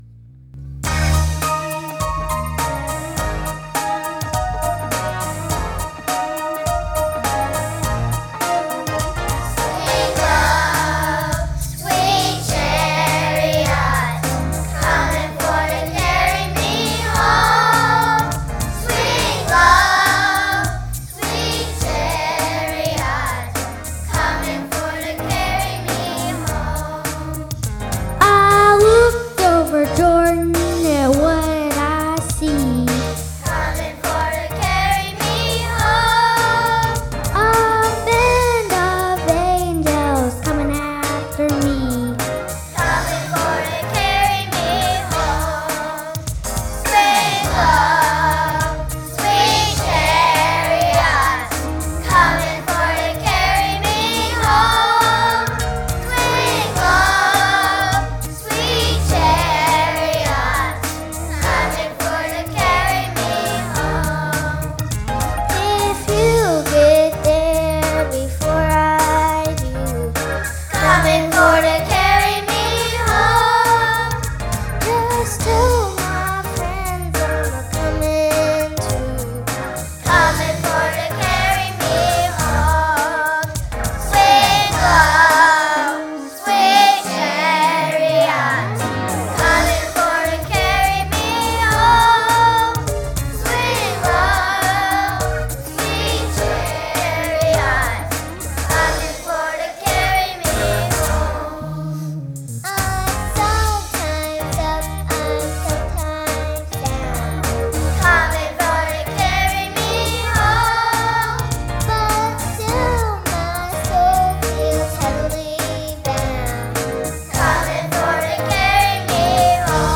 Sunday Morning Music
By God's Kids Choir